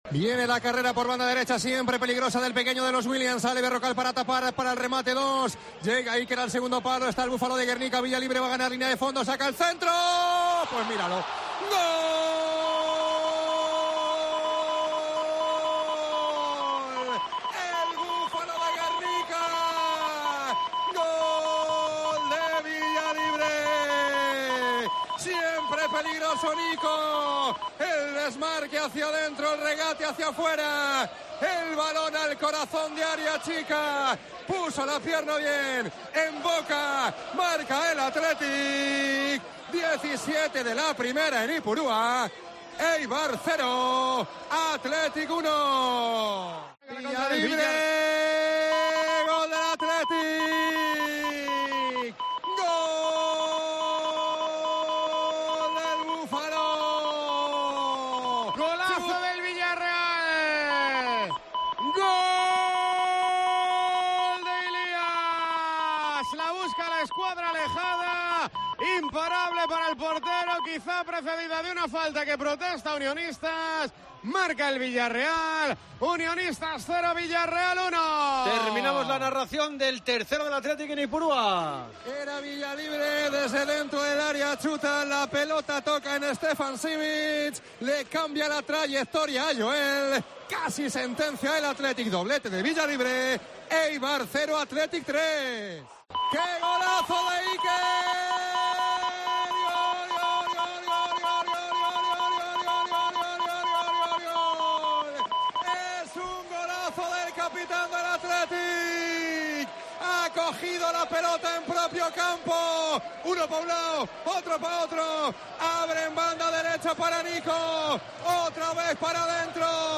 Los goles del Athletic en Tiempo de Juego narrados